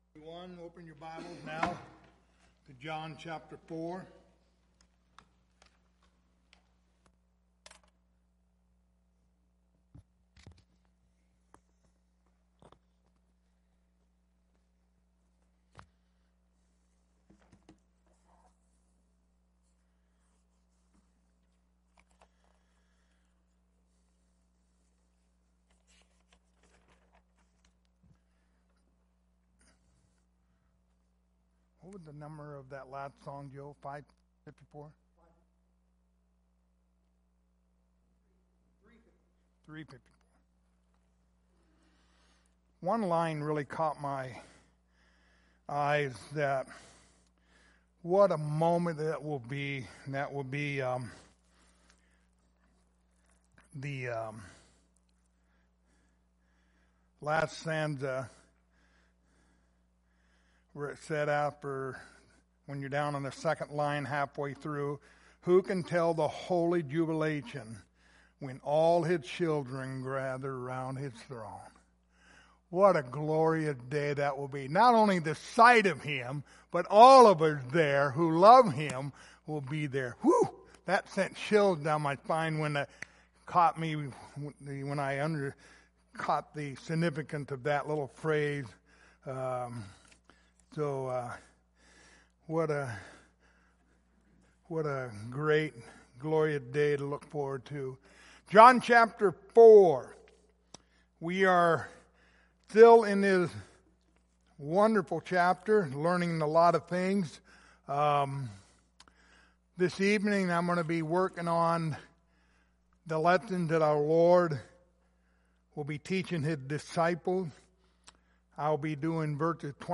Passage: John 4:27-38 Service Type: Wednesday Evening